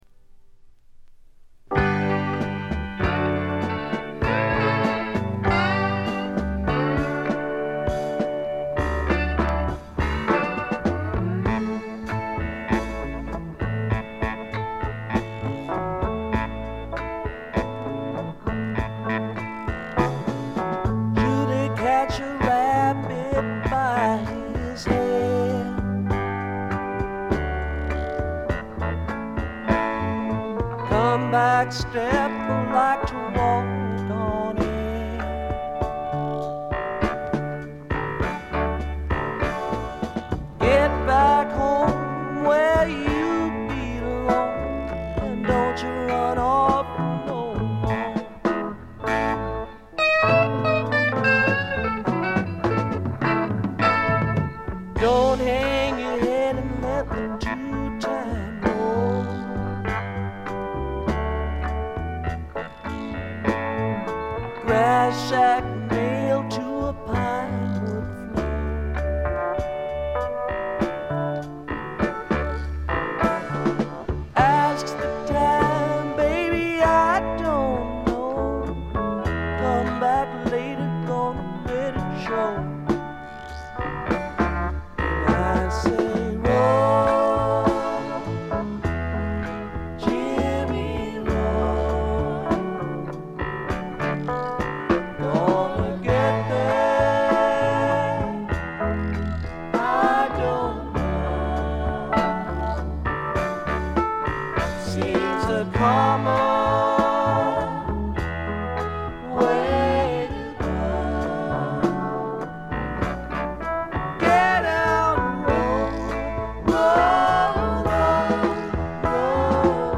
静音部で軽微なバックグラウンドノイズが聴かれる程度。
試聴曲は現品からの取り込み音源です。